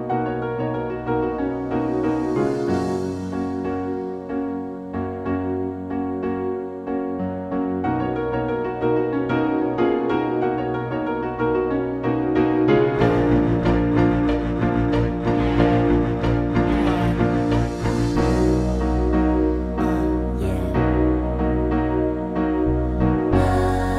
R'n'B / Hip Hop